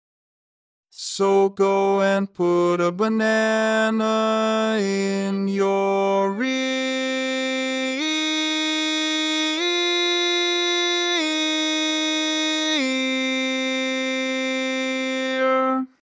Key written in: C Major
Type: Female Barbershop (incl. SAI, HI, etc)